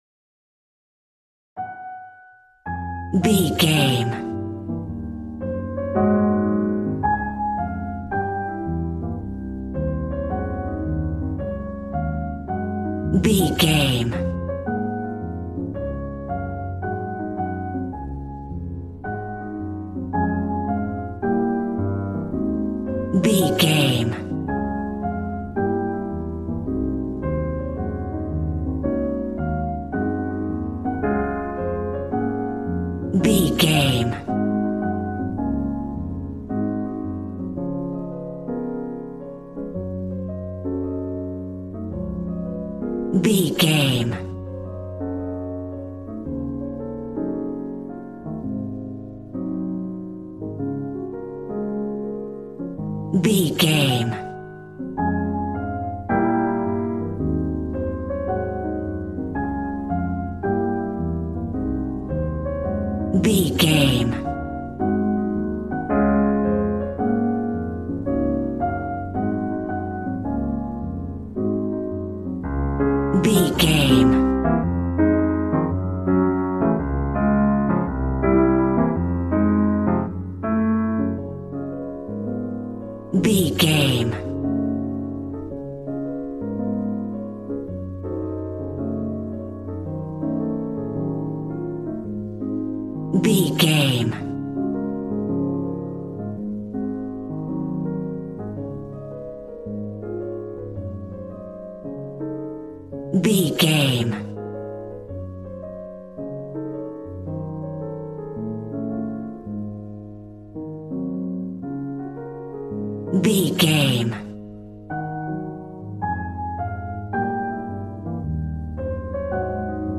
Ionian/Major
D♭
smooth
drums